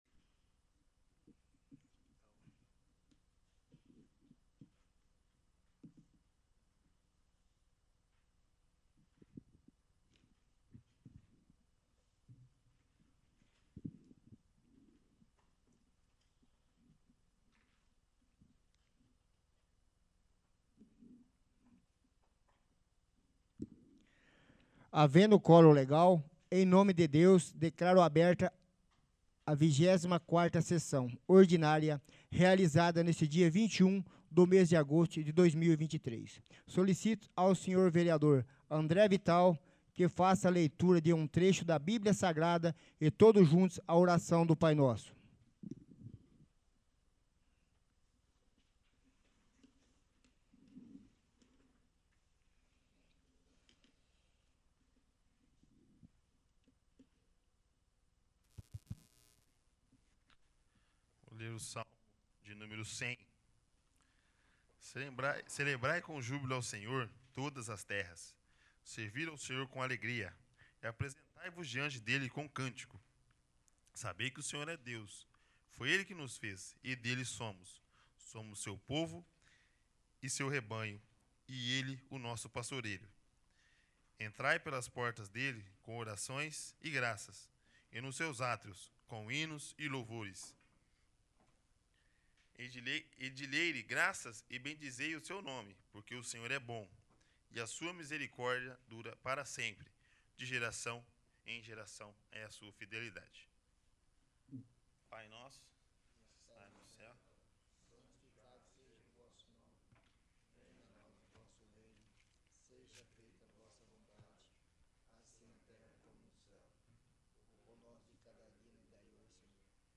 23º. Sessão Ordinária